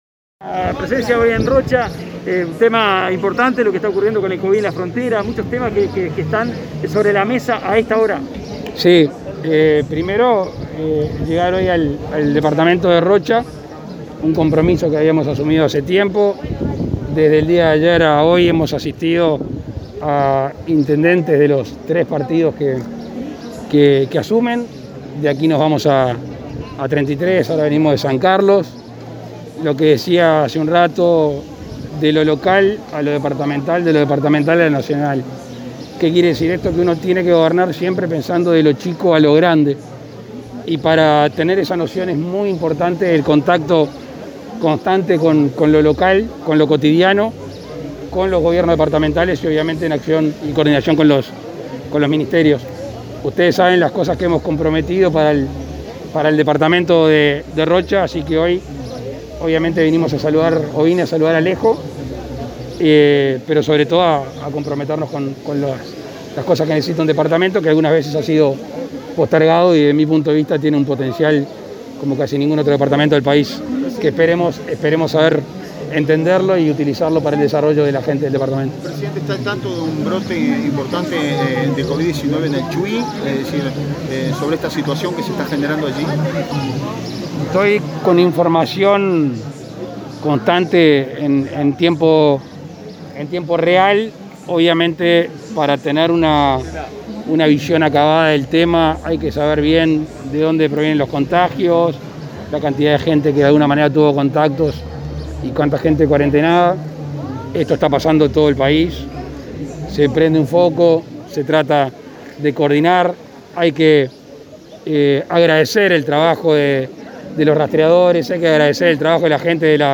Presidente Lacalle Pou participó de asunción de intendente Alejo Umpiérrez 27/11/2020 Compartir Facebook X Copiar enlace WhatsApp LinkedIn Este 27 de noviembre, Alejo Umpiérrez asumió la jefatura de la Intendencia de Rocha, en presencia del presidente Luis Lacalle Pou, quien dijo que su participación forma parte de un compromiso asumido hace tiempo, de fomentar el contacto cotidiano entre los Gobiernos nacional, departamental y municipal. El mandatario conversó con la prensa sobre diversos temas y resaltó el potencial que, a su entender, tiene Rocha.